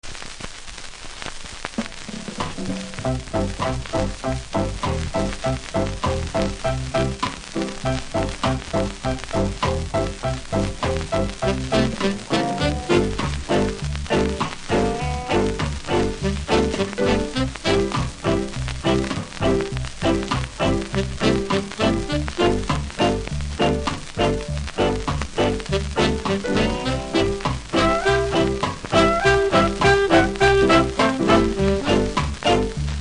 プレス起因のノイズがありますので試聴で確認下さい。